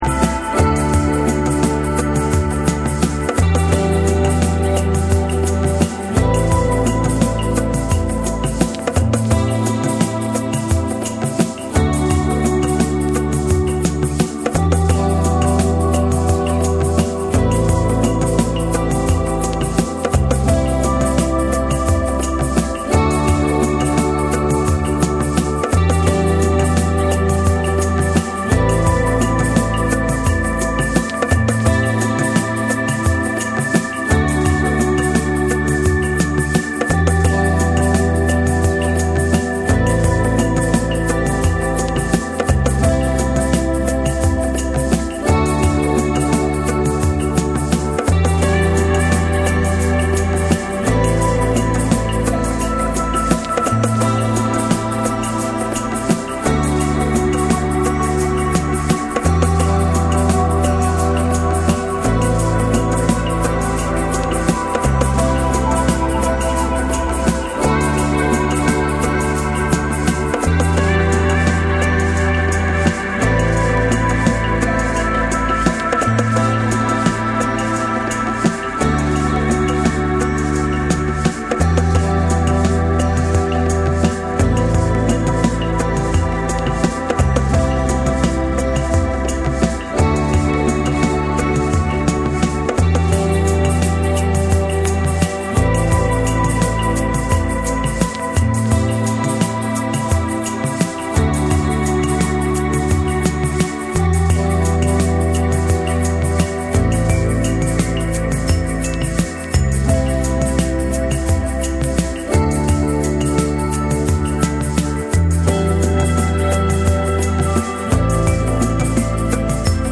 生演奏の質感とエレクトロニックなプロダクションが自然に溶け合った、柔らかく心地よいバレアリック・サウンドを展開。
TECHNO & HOUSE